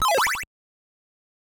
From The Cutting Room Floor Jump to navigation Jump to search File File history File usage Metadata CastlevaniaII-NES-HeartAcqSFX.ogg  (Ogg Vorbis sound file, length 1.5 s, 83 kbps) Summary This file is an audio rip from a(n) NES game.
CastlevaniaII-NES-HeartAcqSFX.ogg